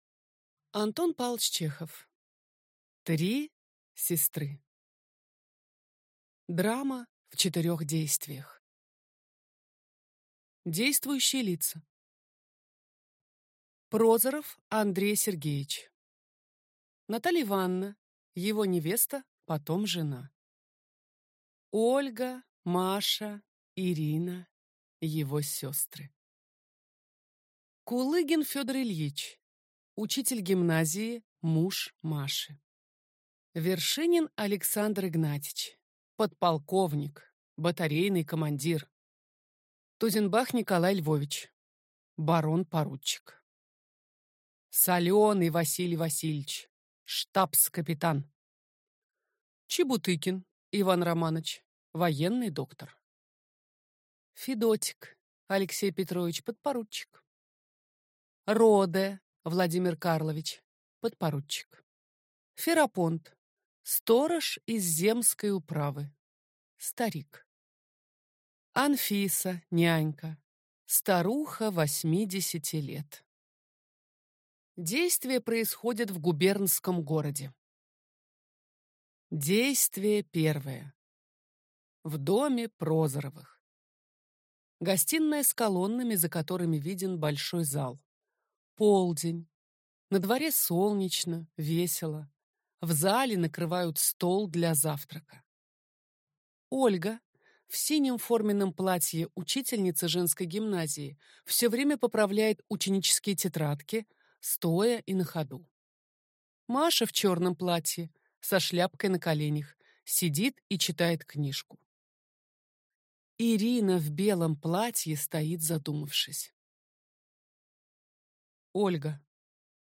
Аудиокнига Три сестры | Библиотека аудиокниг